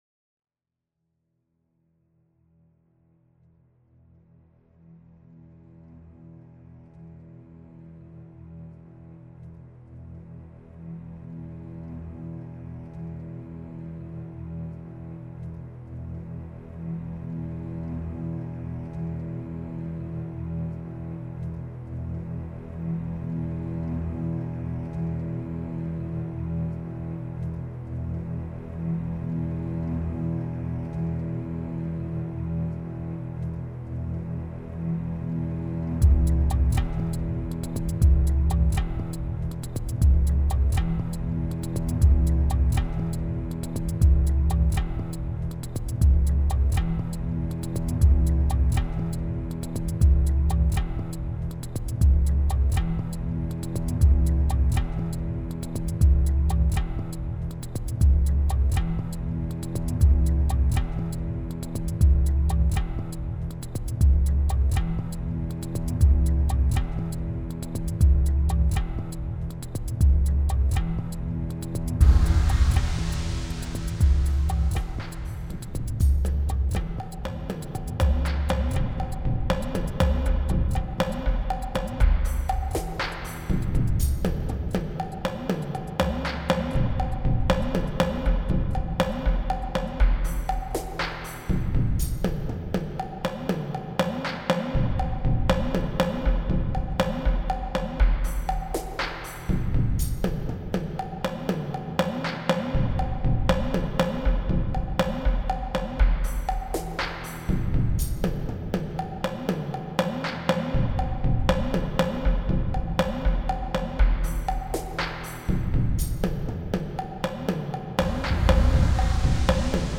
Added guitar and drums to this version.